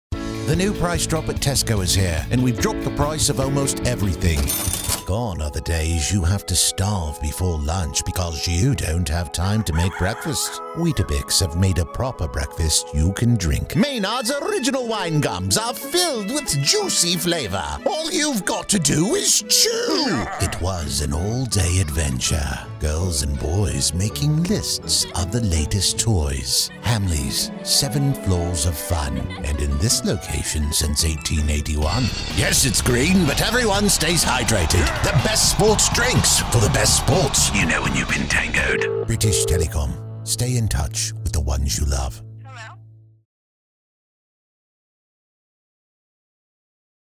Young Adult, Adult
british rp | natural
COMMERCIAL 💸
Brit_mixdown.2mp3.mp3